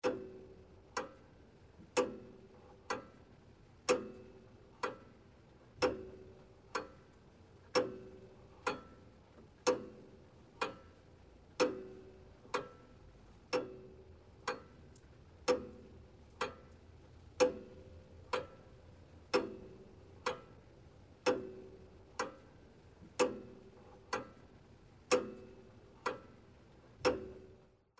Szumy uszne s� zjawiskiem akustycznym polegaj�cym na odczuwaniu d�wi�k�w przy braku zewn�trznego �r�d�a tego d�wi�ku. Opisywane s� przez osoby, kt�re je odczuwaj� jako gwizdy, �wisty, szum wiatru , dzwonienie, bulgotanie, tykanie itp.